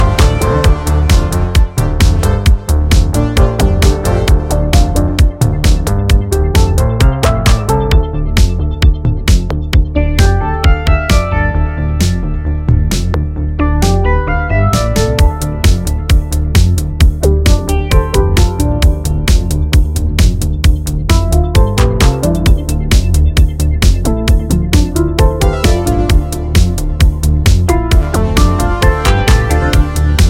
From hypnotic rhythms to enveloping melodies